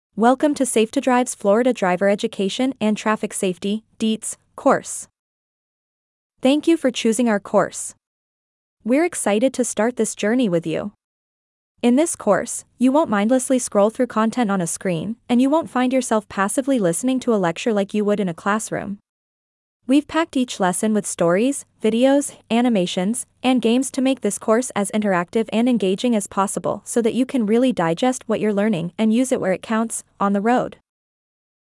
Yes! The course includes a free audio read-along.